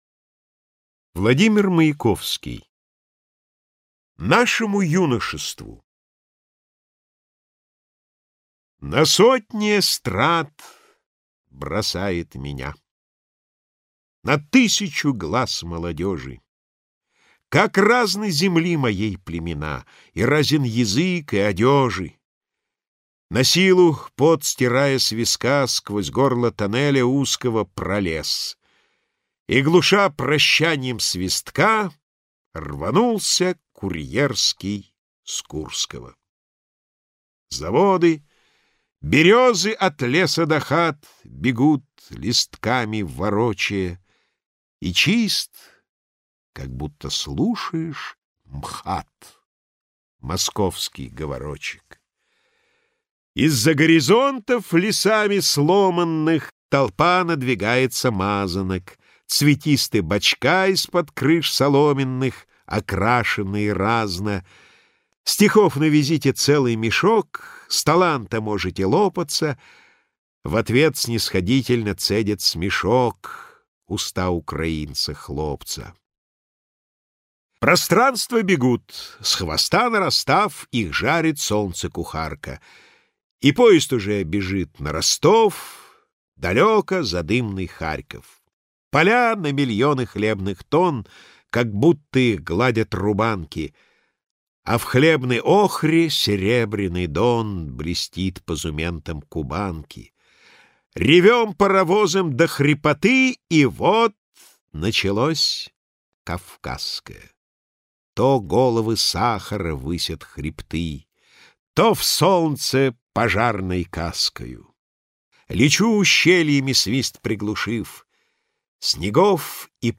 Маяковский издевается Автор Владимир Маяковский Читает аудиокнигу Владимир Самойлов.